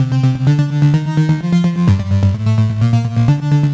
Index of /musicradar/french-house-chillout-samples/128bpm/Instruments
FHC_Arp B_128-C.wav